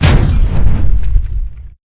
fireball_02.WAV